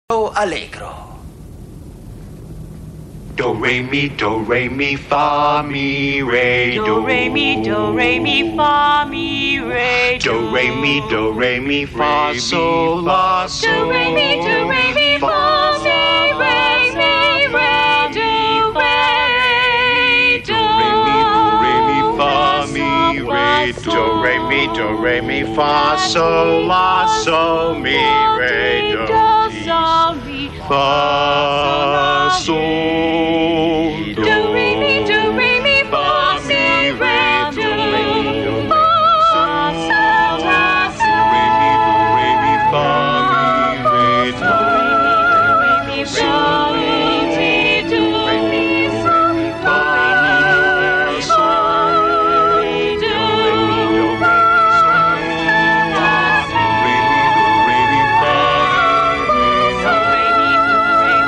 Kanon-Melodie